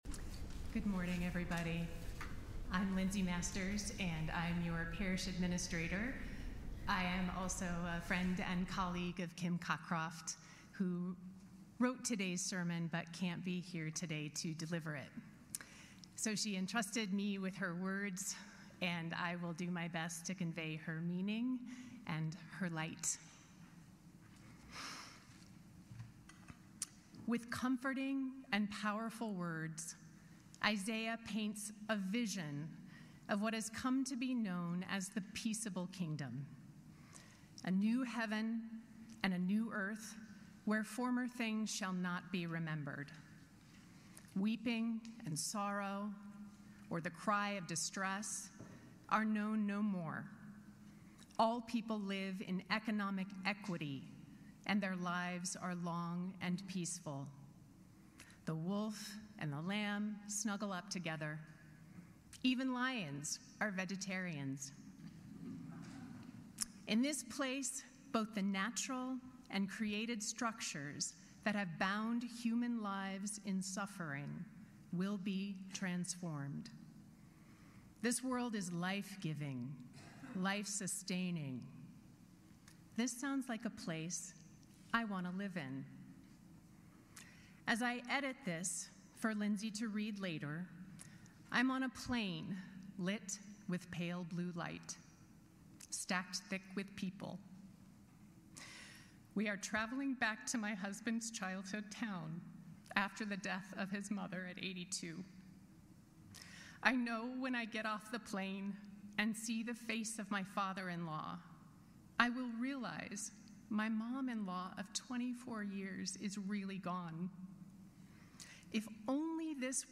Sermons | Grace Episcopal Church
sermon